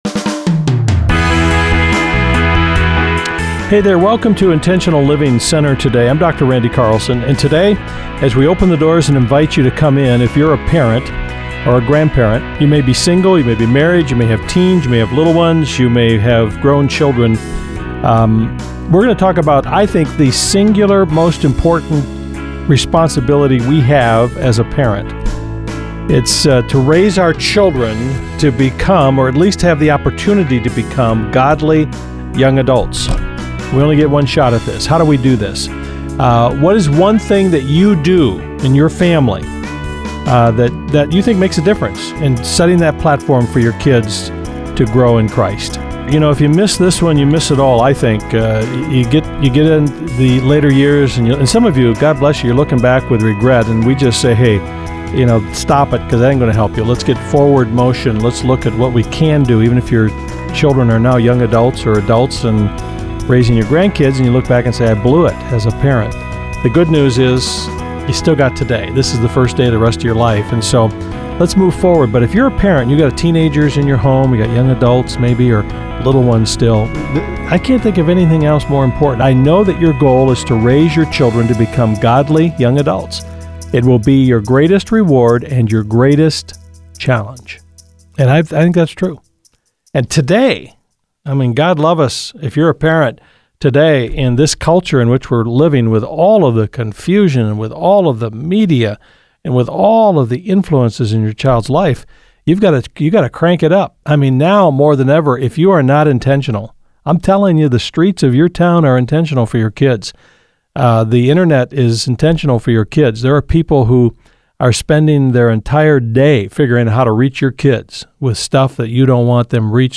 Intentional Living is a nationally-syndicated program on more than 250 radio stations including Family Life Radio.